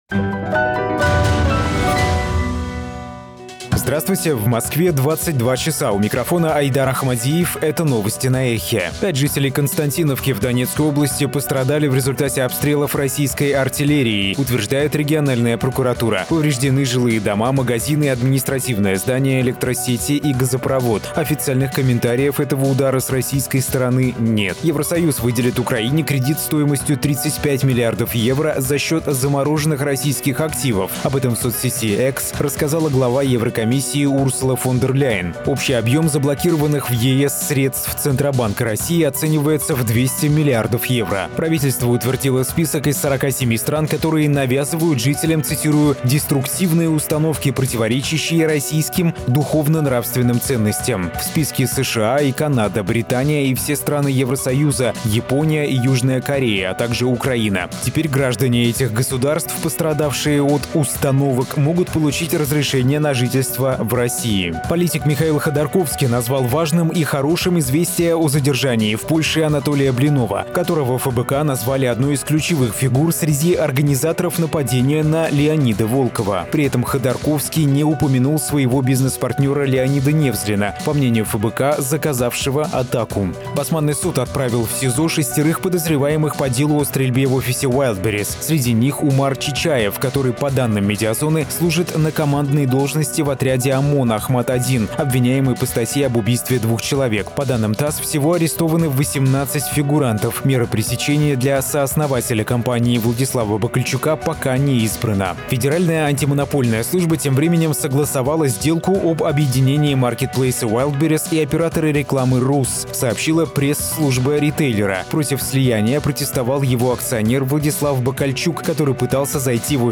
Новости 22:00